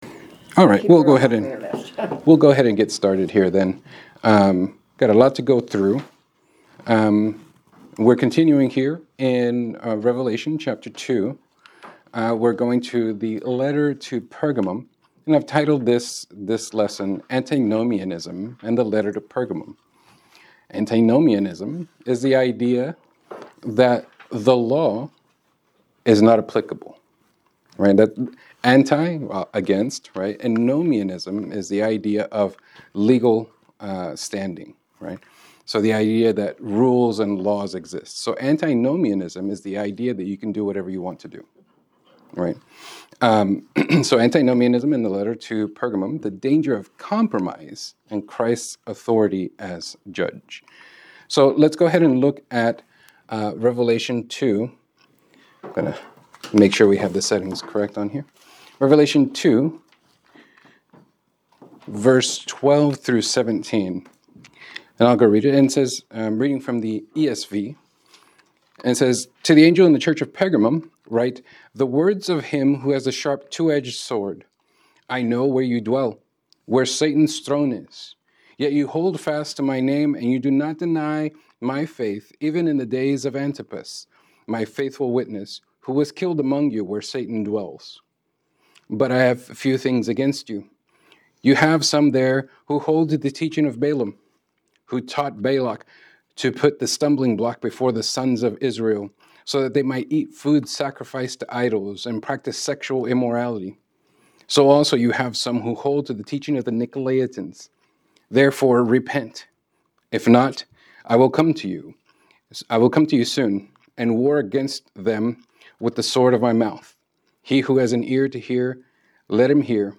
Revelation 2:12-17 Class Recording